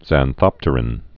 (zăn-thŏptər-ĭn)